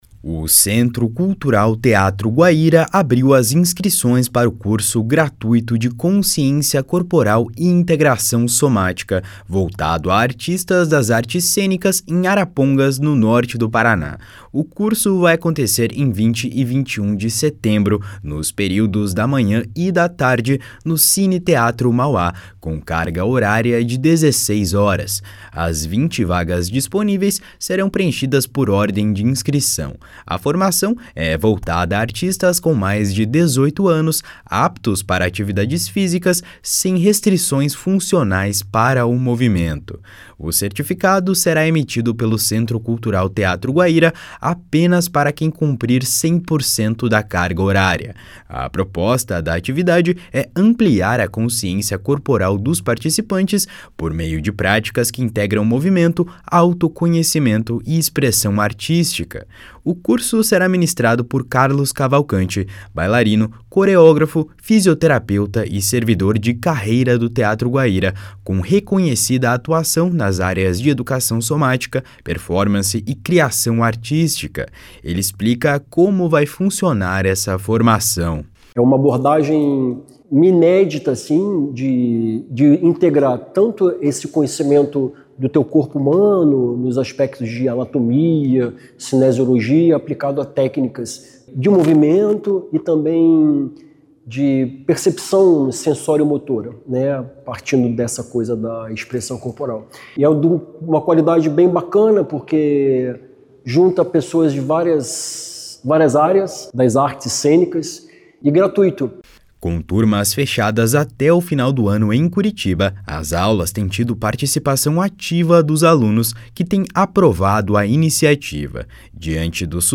Ele explica como vai funcionar essa formação.